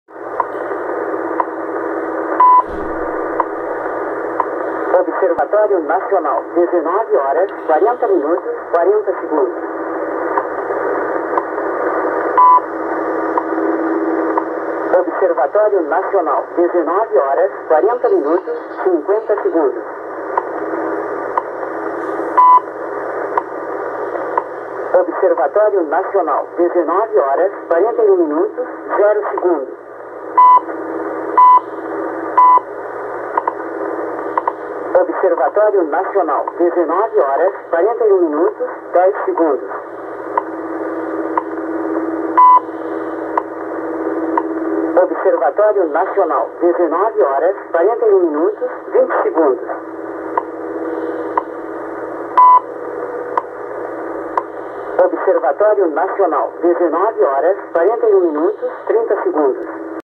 Arquivo sonoro com trecho da transmissão regular do Observatório Nacional do Rio de Janeiro em 2/11/2017 na faixa de 10 mHz